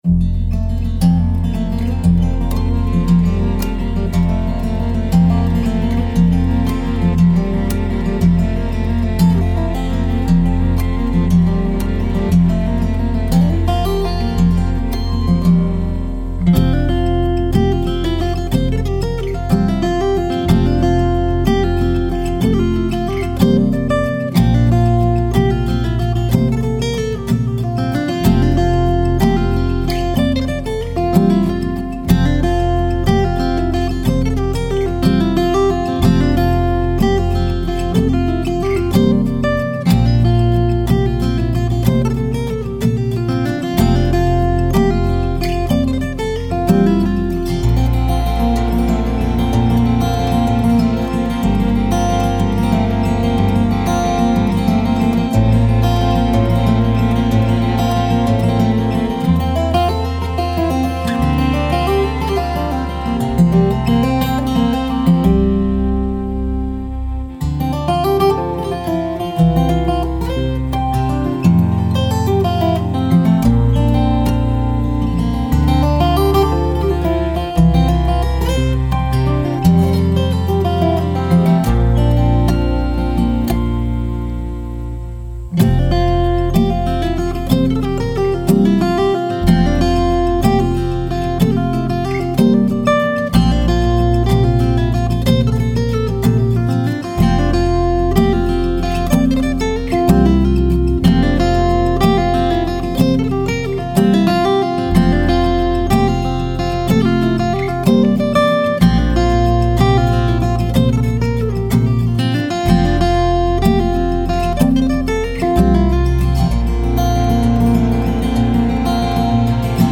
Acoustic Guitar Recordings